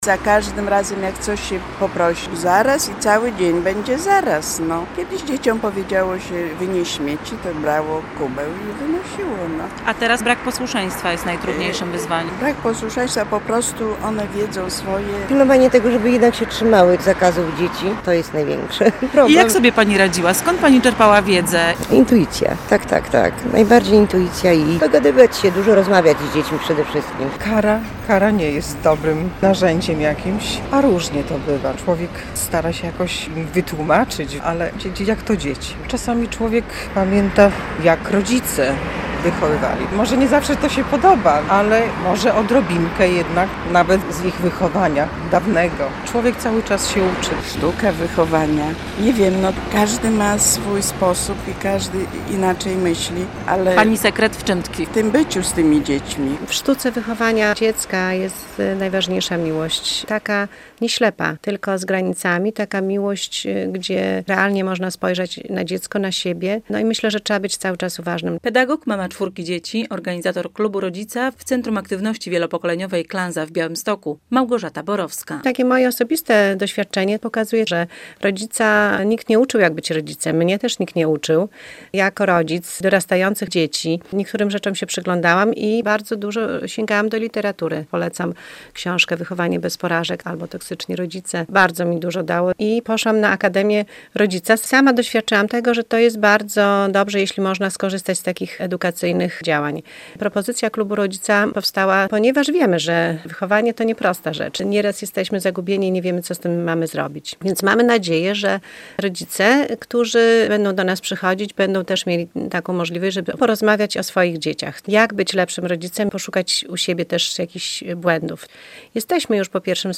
Sztuka wychowania - relacja